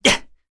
Ezekiel-vox-Landing_kr.wav